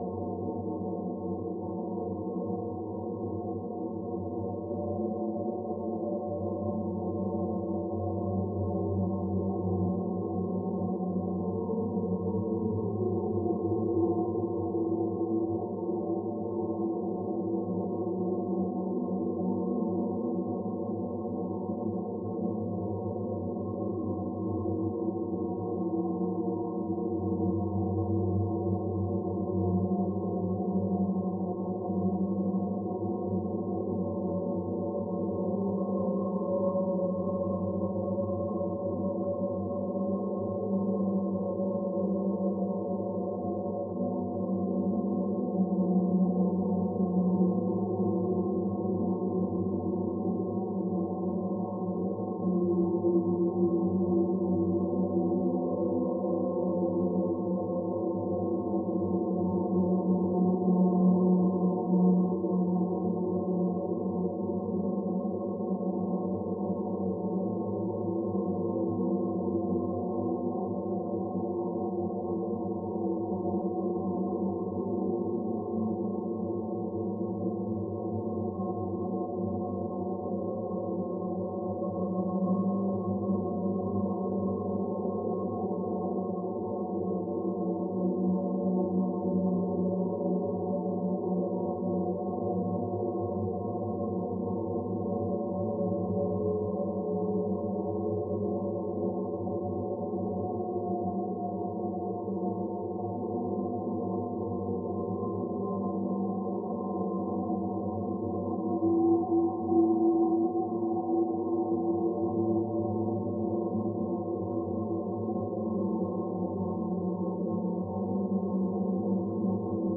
skyclad_sound_ambience_dark_loop_dynamic_tones_howling_moaning_mournful_eerie_105
Tags: ghost